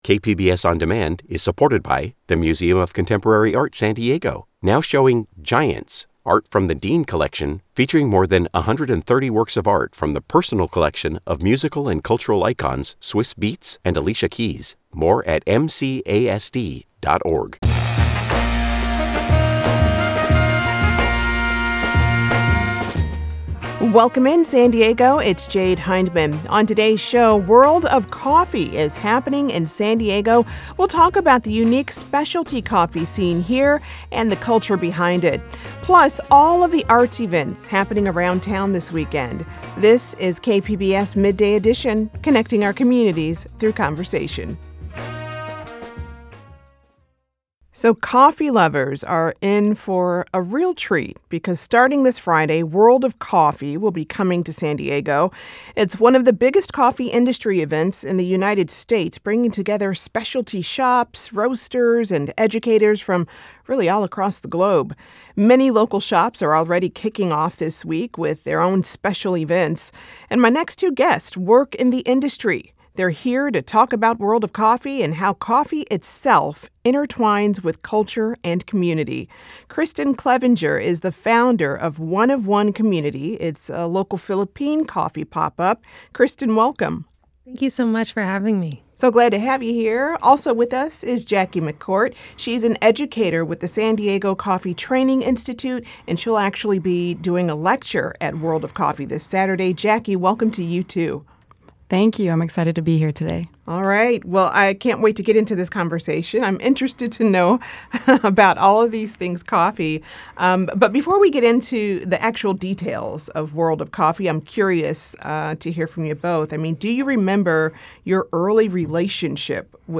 World of Coffee will be kicking off at the San Diego Convention Center Friday, bringing together specialty coffee shops, roasters, educators and experts from all across the globe. Thursday on Midday Edition, we sit down with a local business owner and a coffee educator to talk about what it means to bring World of Coffee to San Diego, and how coffee itself is intertwined with culture and community.